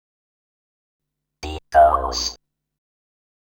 Techno / Voice / VOICEFX164_TEKNO_140_X_SC2.wav